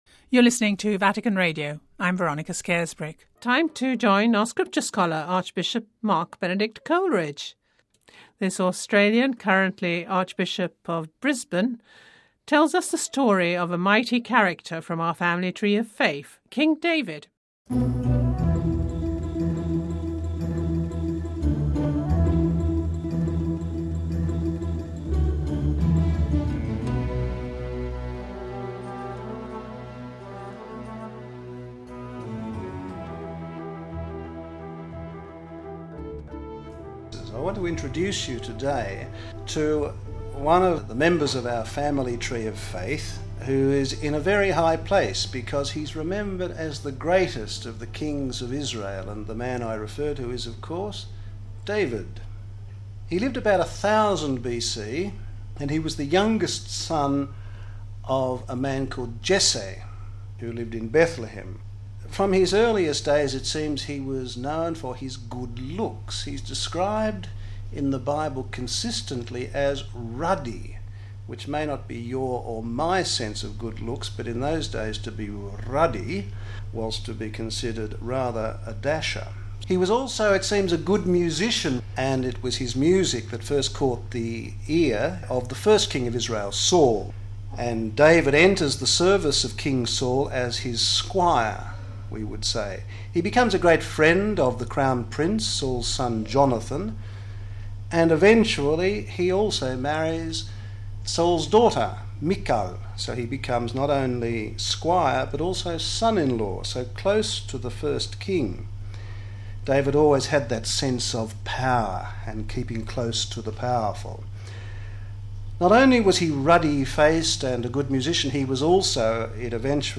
Find out more from scripture scholar, Archbishop of Brisbane Mark Coleridge.